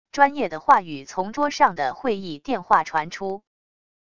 专业的话语从桌上的会议电话传出wav音频